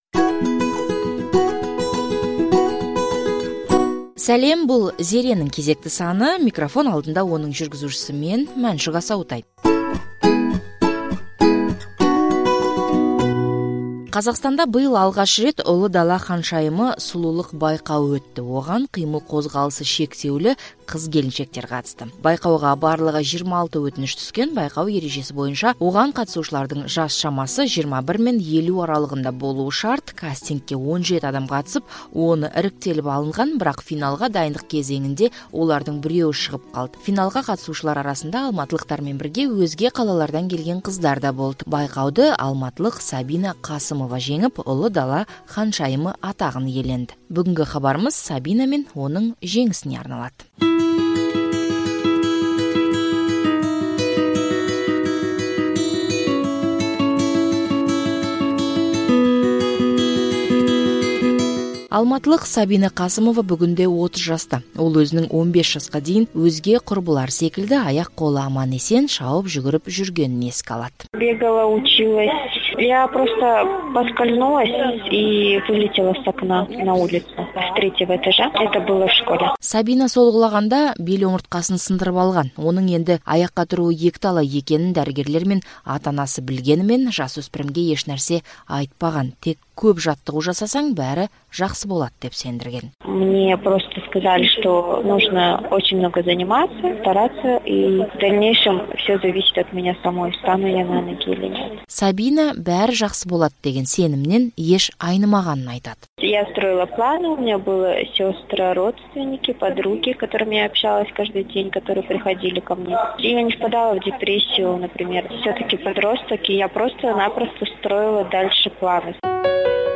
сұқбат.